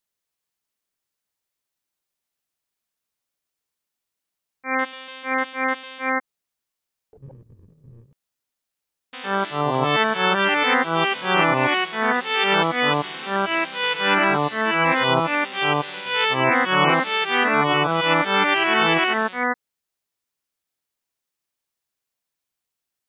以下の例では、刻々と途中でテンポを変更している。